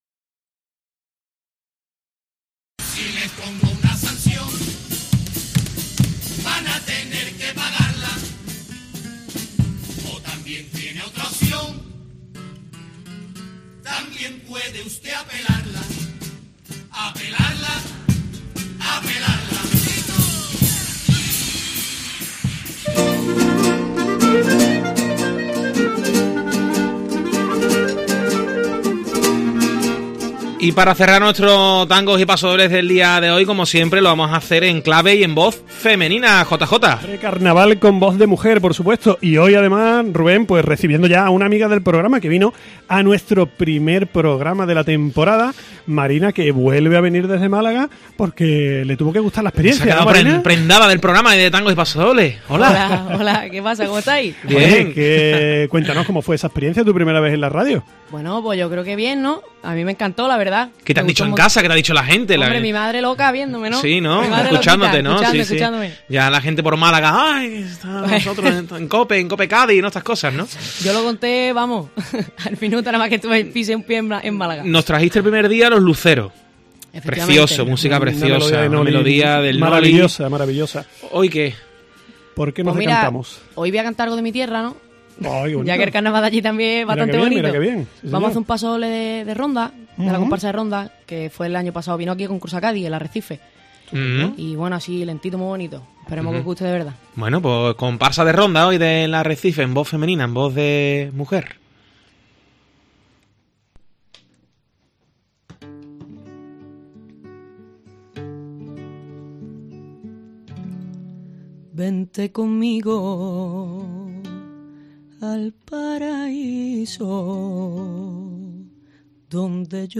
pasodoble
Carnaval